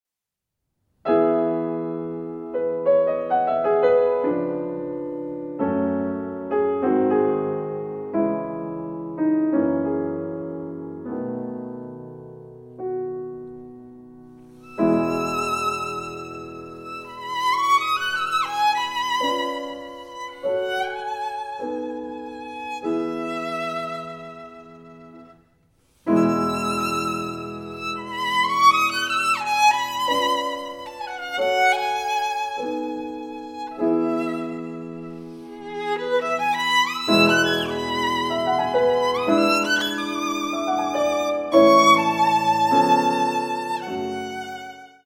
for violin and piano
violin
piano